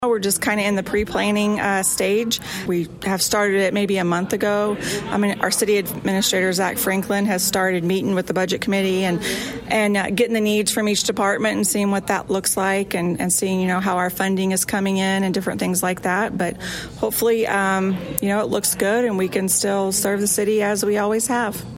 Park Hills Mayor Stacey Easter discussed the city’s budget planning process during her State of the City address at the Park Hills - Leadington Chamber Luncheon.
Park Hills, Mo. (KFMO) - Park Hills Mayor Stacey Easter delivered a State of the City address during Tuesday’s Park Hills - Leadington Chamber of Commerce Luncheon, providing updates on city progress and a look ahead at what's to come.